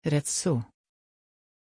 Pronunciation of Rezzo
pronunciation-rezzo-sv.mp3